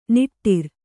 ♪ niṭṭir